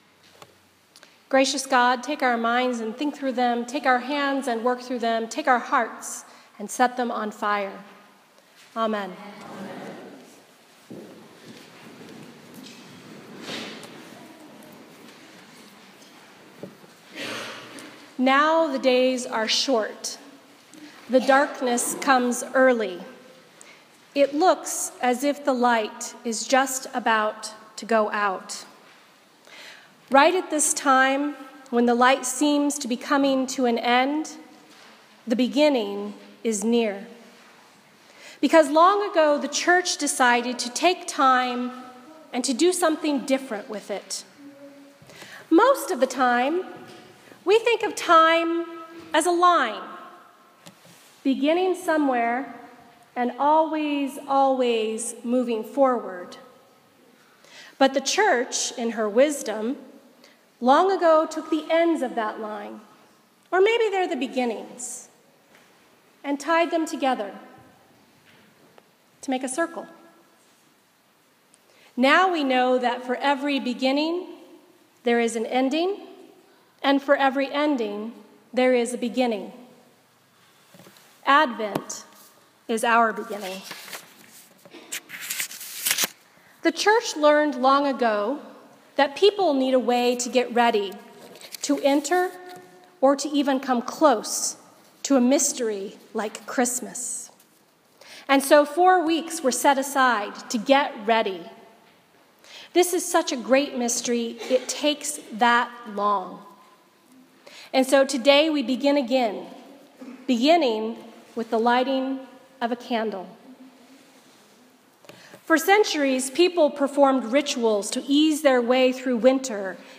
A Sermon for the First Sunday in Advent 2015